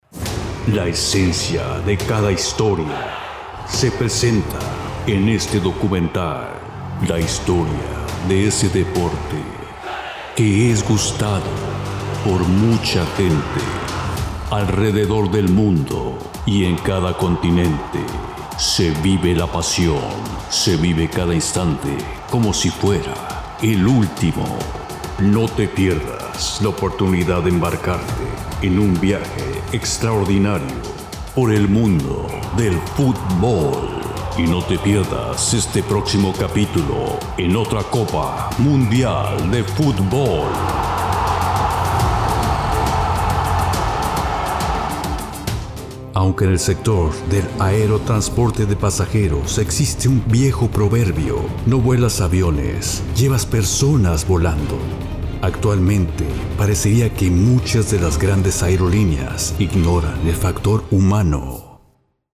Male
Voice over Talent with Deep , nice and friendly , Spanish accent for english spots and translations.
Narration
Words that describe my voice are Deep, Comercial, promo.
All our voice actors have professional broadcast quality recording studios.
0515Narrations_.mp3